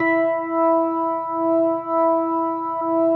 B3LESLIE E 5.wav